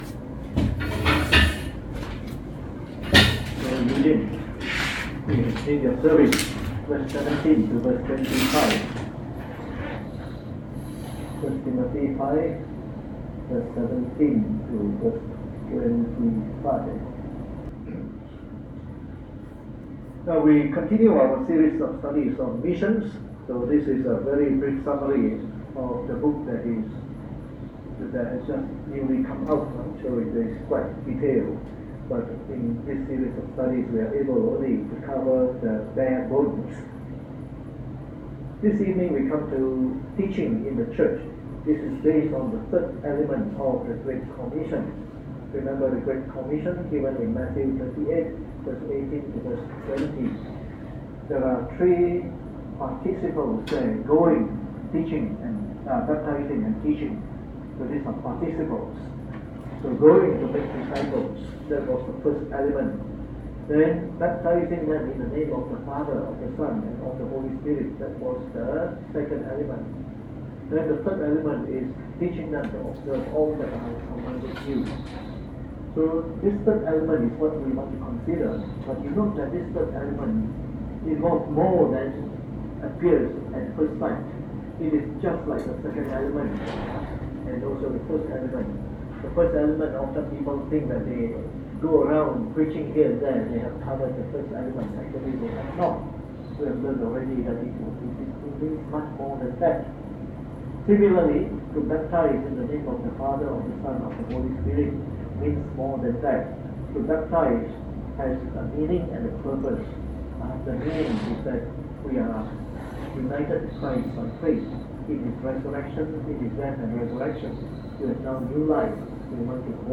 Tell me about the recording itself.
Preached on the 23th of January 2019 during the Bible Study, from our series on Missions.